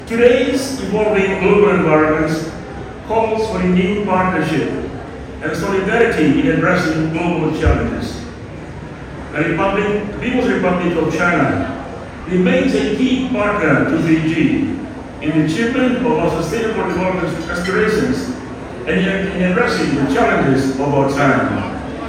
Deputy Prime Minister Viliame Gavoka during the Fiji-Chinese celebration of China’s 74th Independence Day and the Mid-Autumn Festival in Suva today.